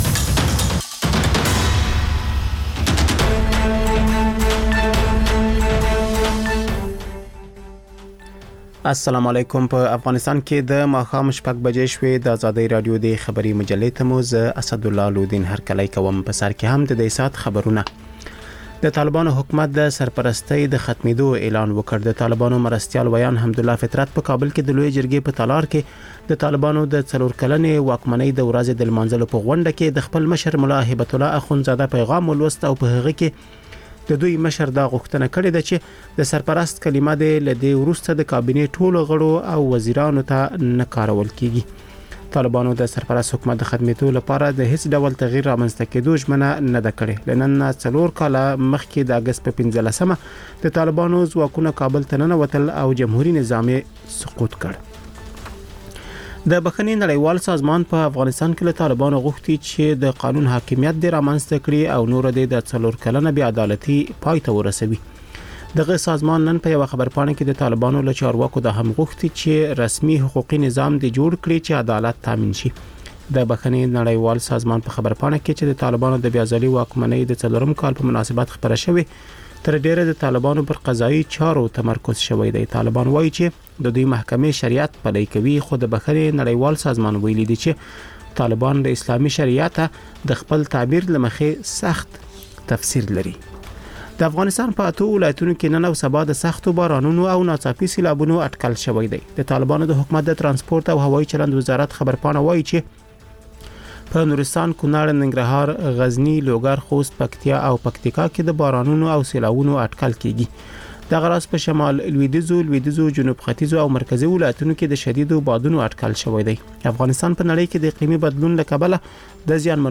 ژوندي نشرات - ازادي راډیو